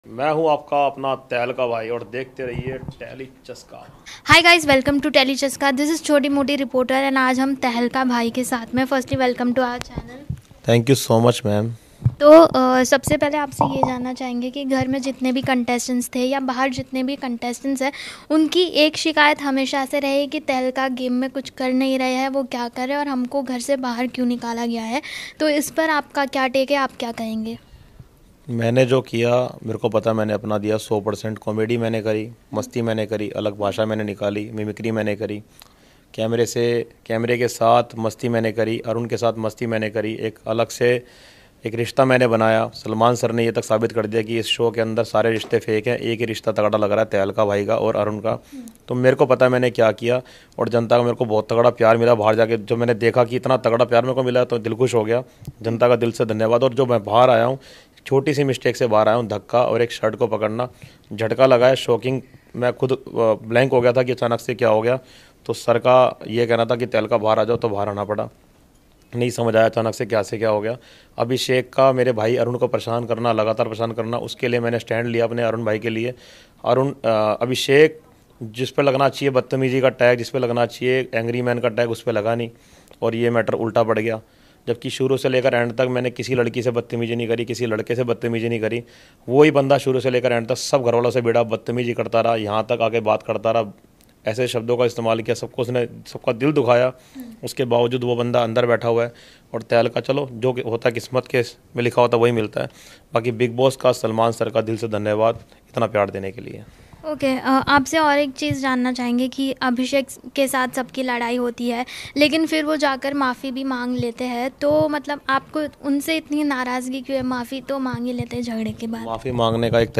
Tehelka-Bhai-Sunny-Arya-1st-Interview-After-Eviction-Bigg-Boss-17.mp3